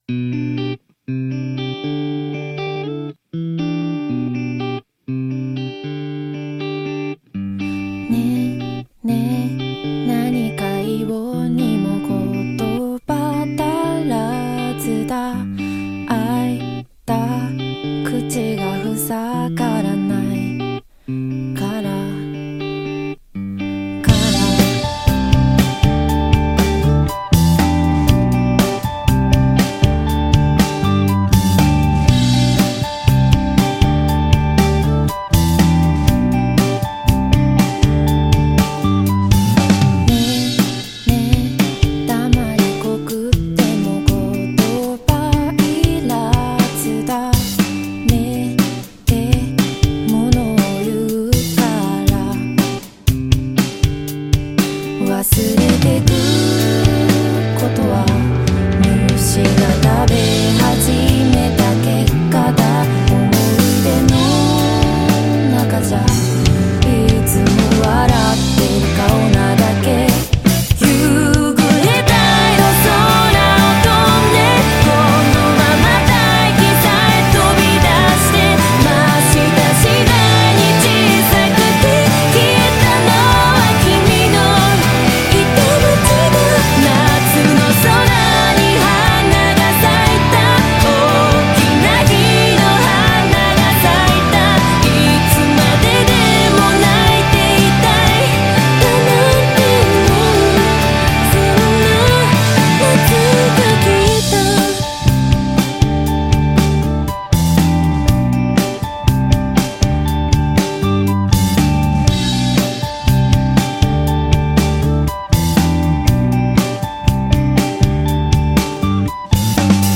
BPM120
Audio QualityPerfect (High Quality)